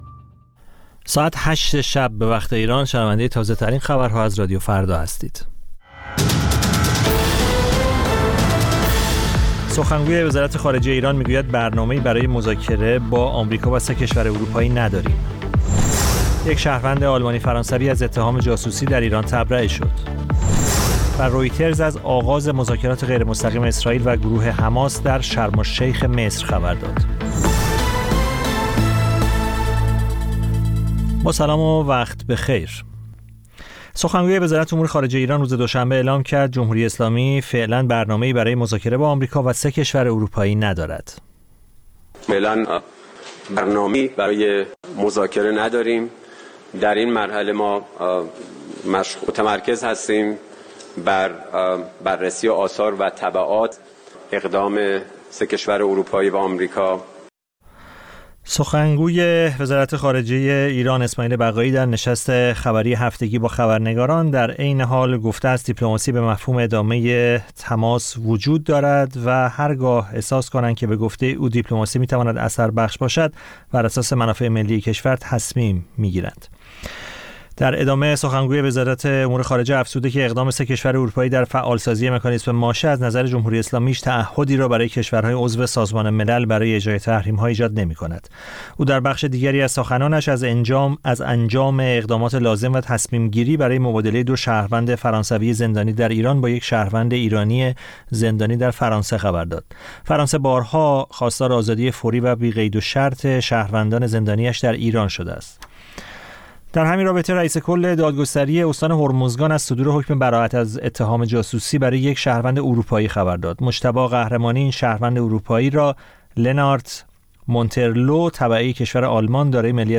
سرخط خبرها ۲۰:۰۰